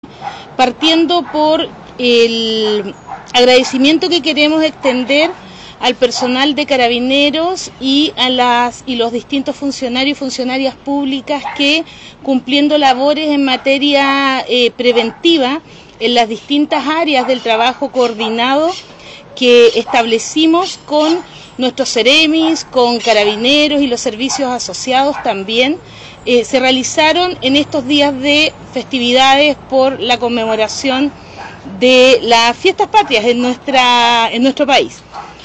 01-Delegada-Paola-Pena-Balance-Fiestas-Patrias.mp3